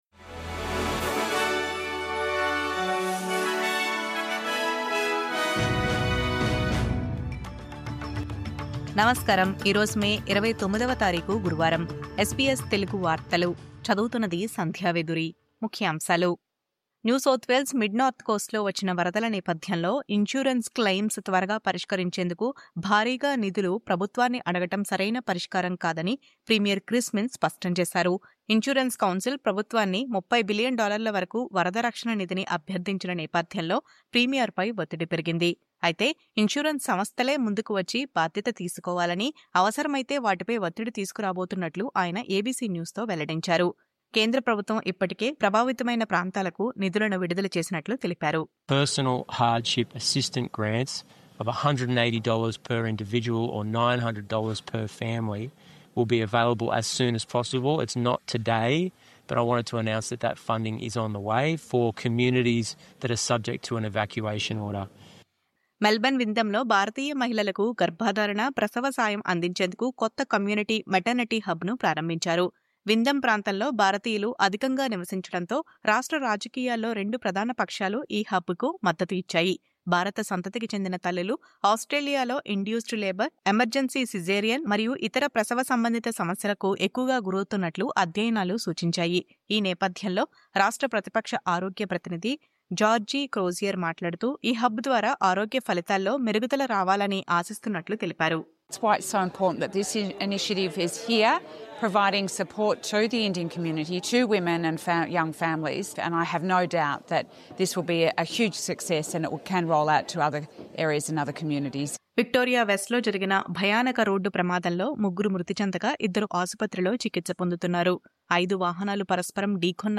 SBS తెలుగు వార్తలు..